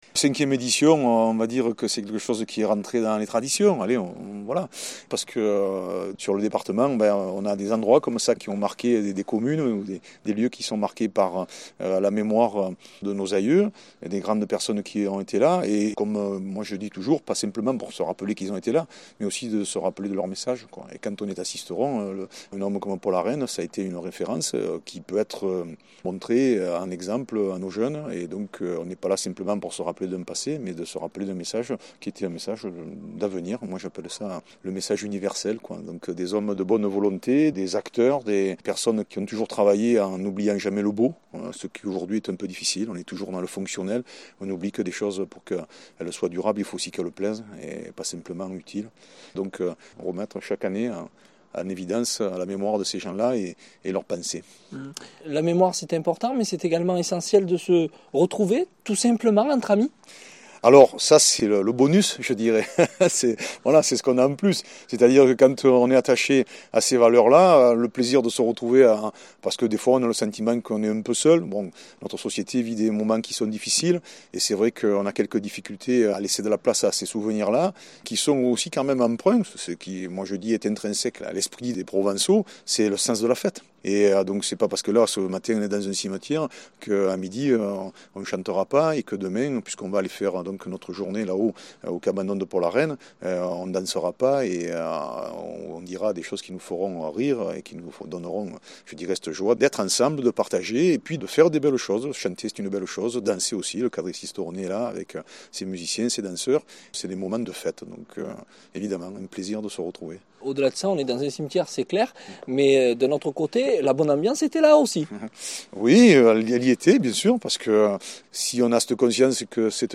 C’est ce qu’il confie au micro